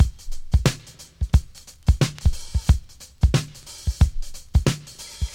• 90 Bpm Drum Loop B Key.wav
Free breakbeat sample - kick tuned to the B note. Loudest frequency: 870Hz
90-bpm-drum-loop-b-key-gSF.wav